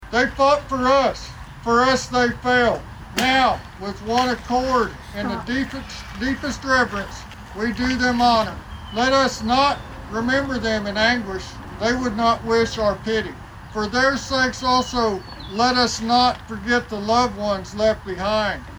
Veterans Remembered at Ceremony in Ramona